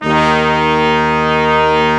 Index of /90_sSampleCDs/Roland LCDP06 Brass Sections/BRS_Quintet/BRS_Quintet % wh